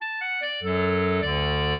clarinet
minuet0-8.wav